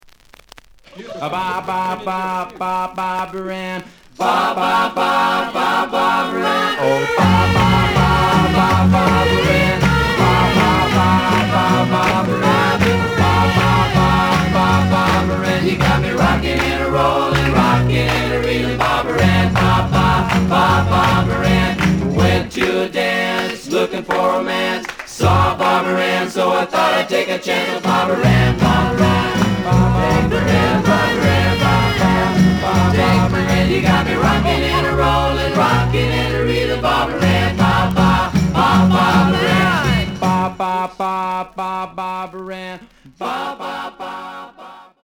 試聴は実際のレコードから録音しています。
The audio sample is recorded from the actual item.
●Genre: Rock / Pop